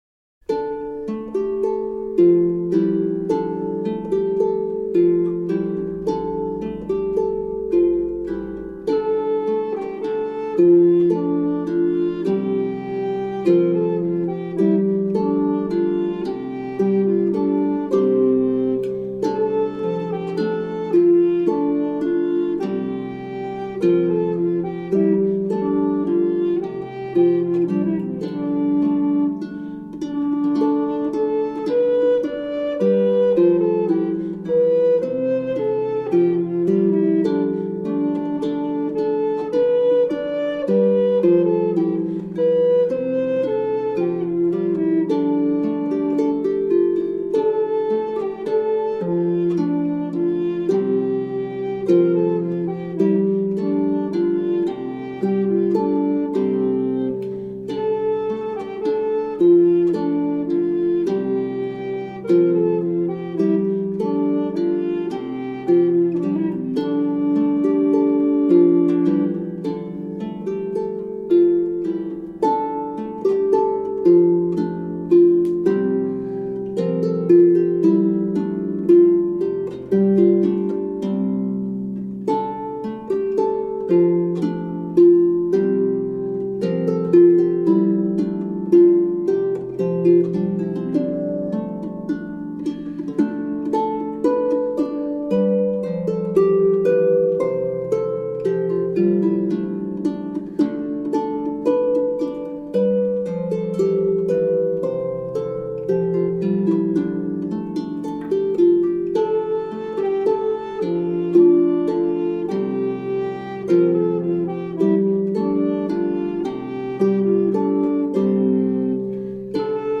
Early music for healing.